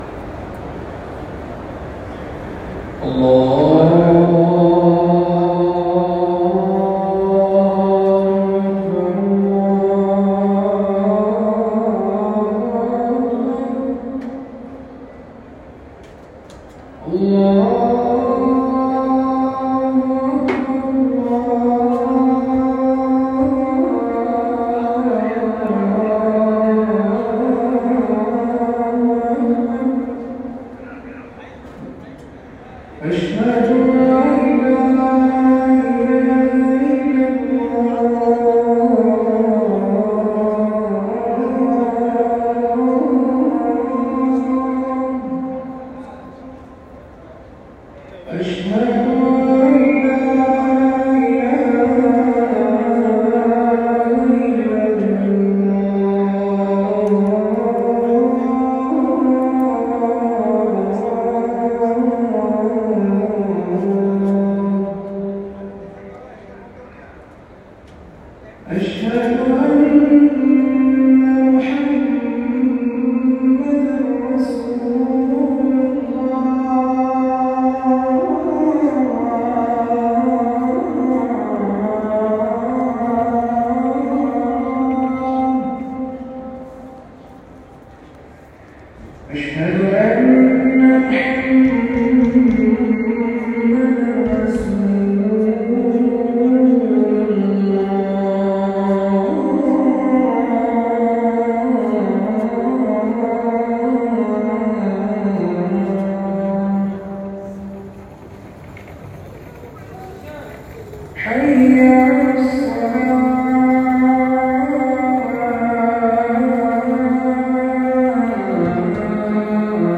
الأذان الأول لصلاة الفجر